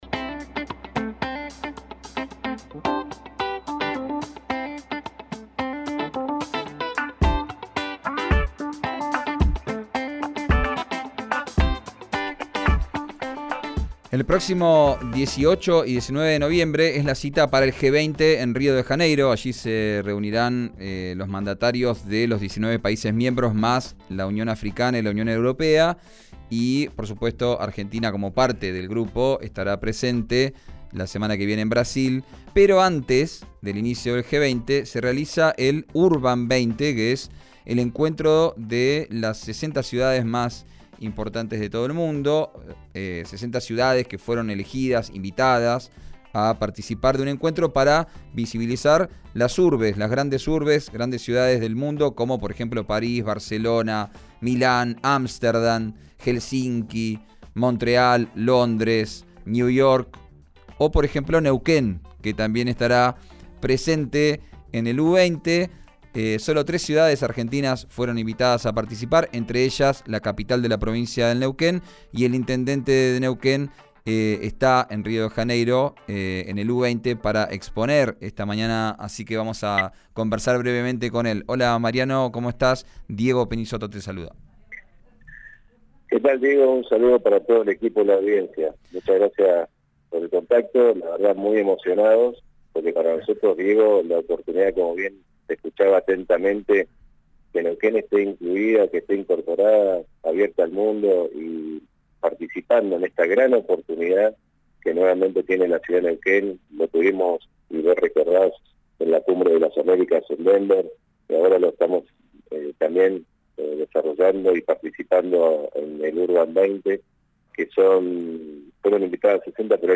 Escuchá al intendente Mariano Gaido en Vos al Aire por RÍO NEGRO RADIO